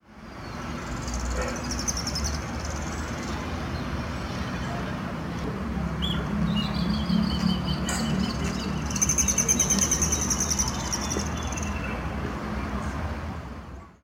Sooty Tyrannulet (Serpophaga nigricans)
Sex: Indistinguishable
Location or protected area: Reserva Ecológica Costanera Sur (RECS)
Condition: Wild
Certainty: Recorded vocal